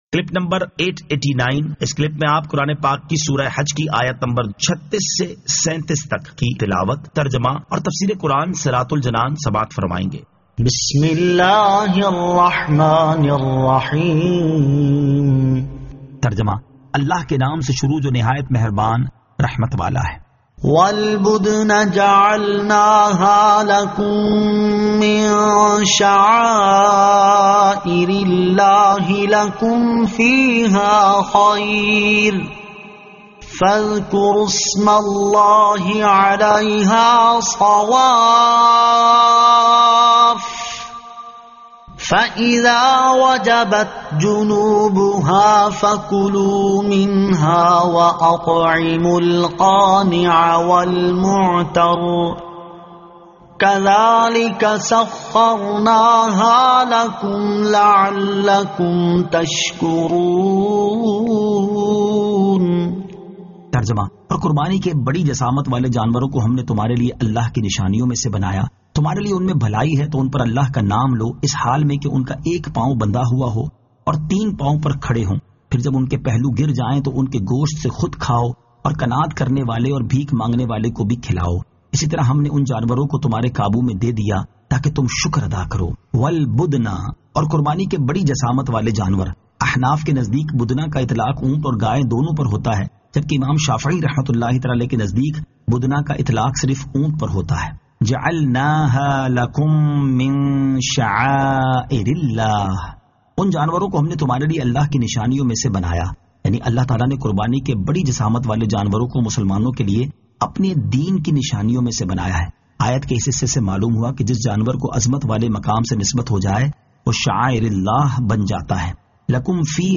Surah Al-Hajj 36 To 37 Tilawat , Tarjama , Tafseer